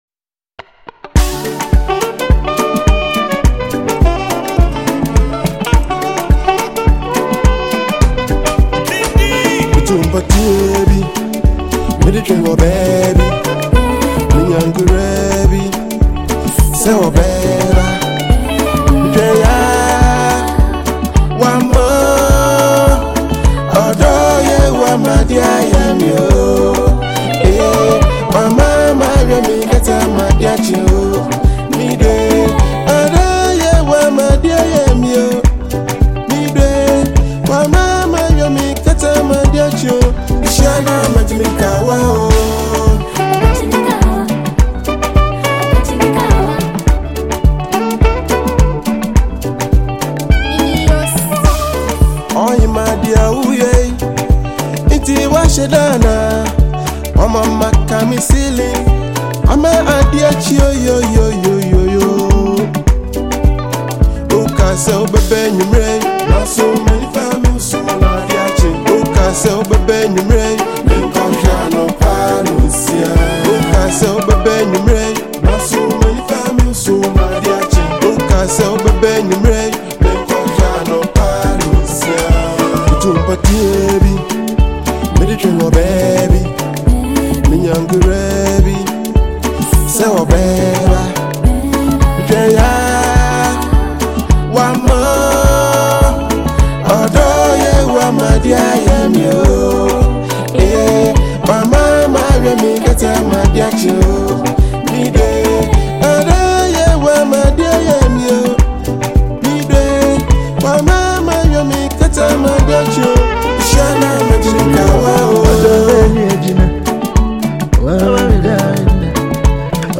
soothing voice
• Genre: Afro-Pop / Love Song